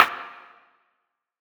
SNARE - INTERLUDE.wav